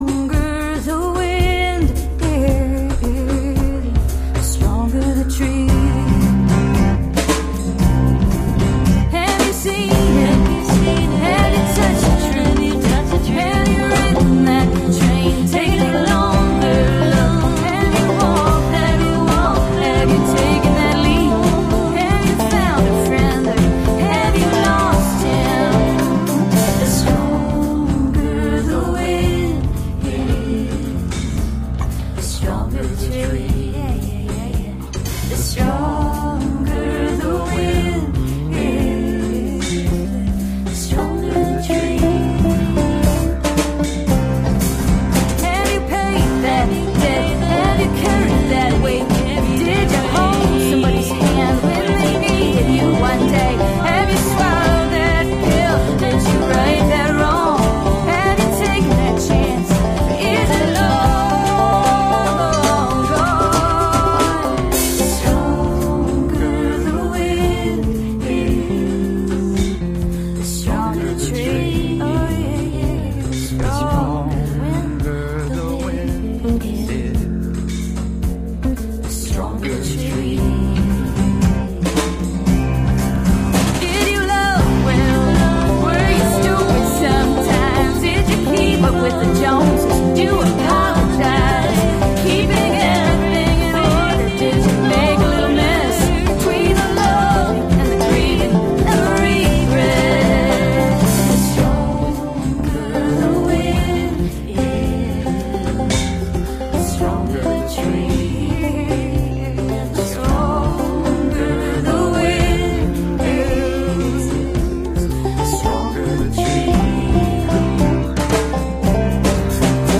a roots and acoustic music marathon webcast